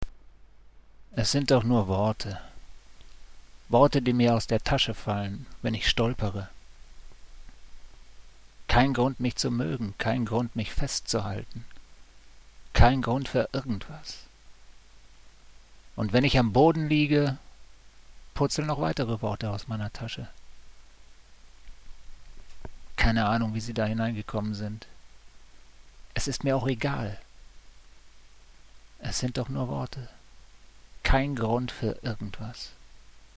(Inwendig vorgetragen:)